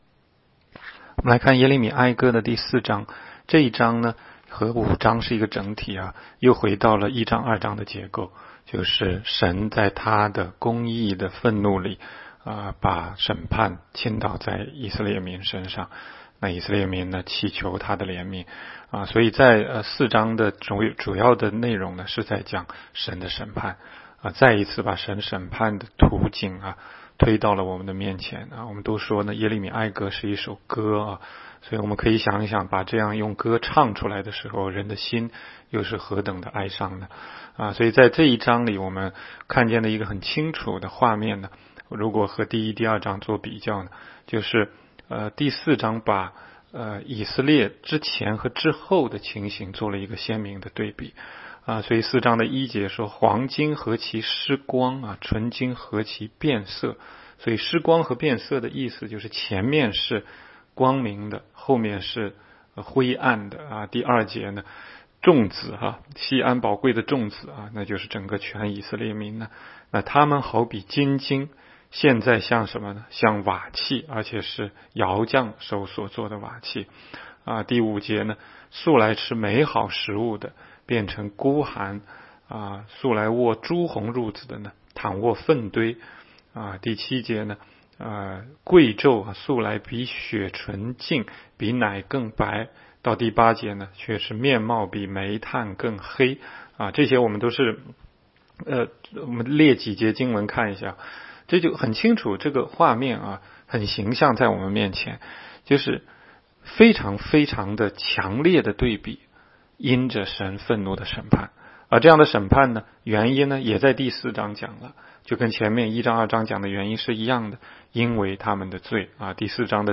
16街讲道录音 - 每日读经 -《耶利米哀歌》4章